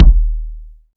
KICK.83.NEPT.wav